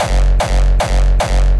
硬式踢腿3 150BPM
描述：150BPM Hardstyle kick
Tag: 150 bpm Hardstyle Loops Drum Loops 277.24 KB wav Key : Unknown